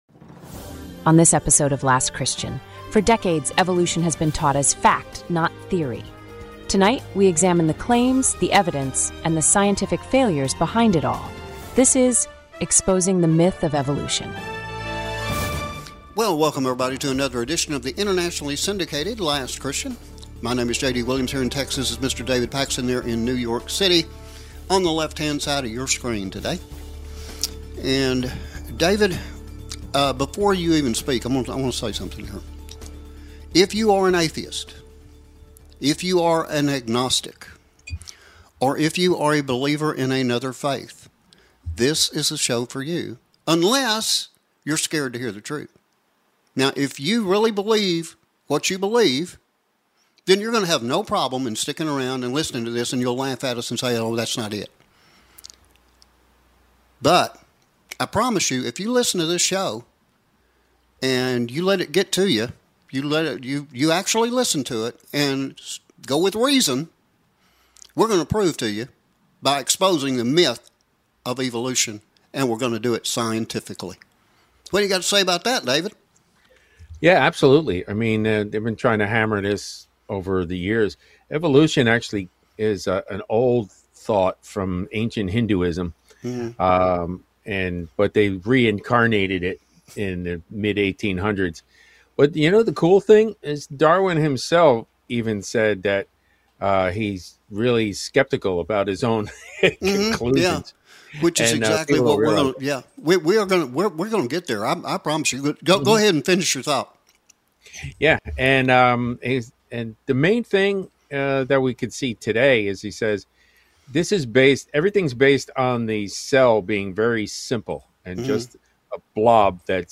faith-based discussion designed to encourage thoughtful analysis rather than hype or speculation.